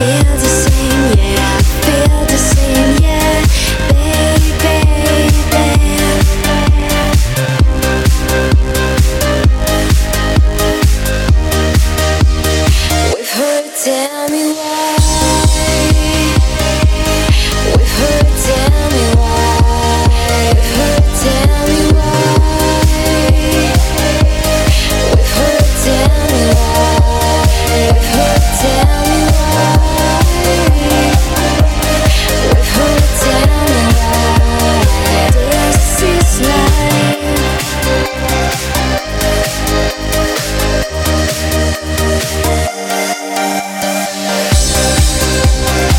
• Качество: 256, Stereo
громкие
красивые
женский вокал
Electronic
EDM
club
progressive trance
Trance